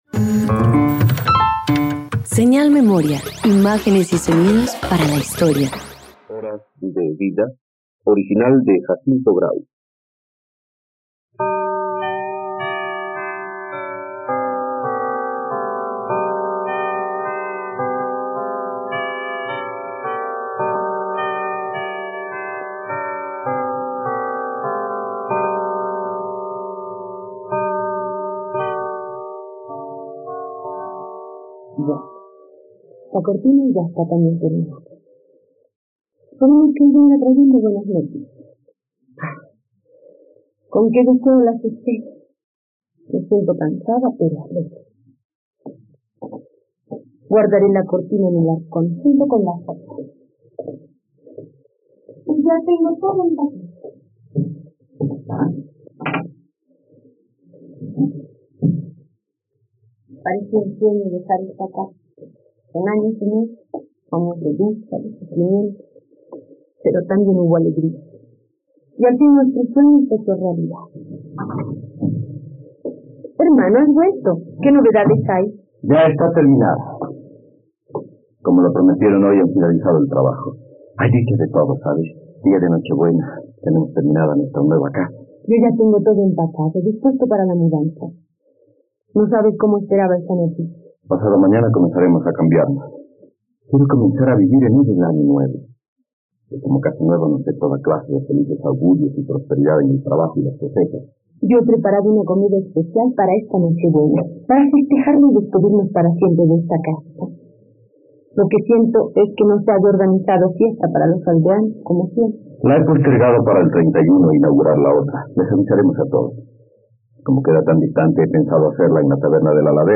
Horas de vida - Radioteatro dominical | RTVCPlay